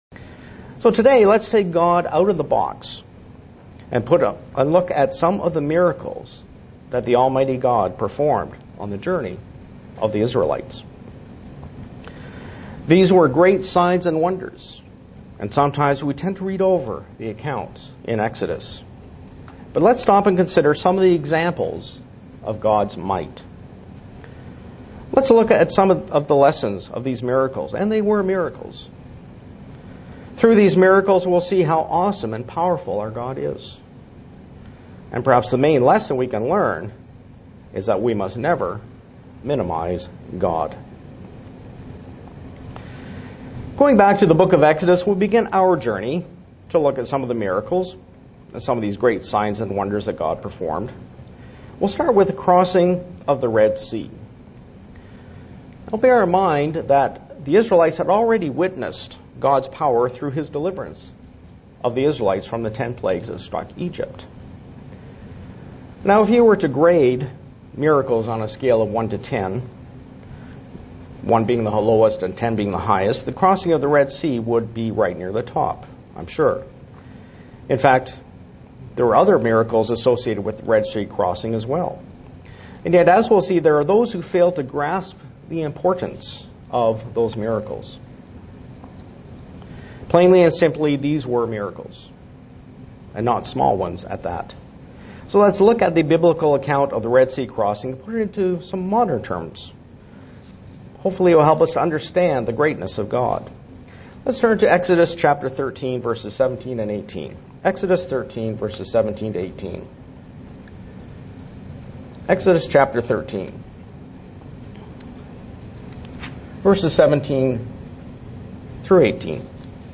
SEE VIDEO BELOW UCG Sermon Studying the bible?
Given in Buffalo, NY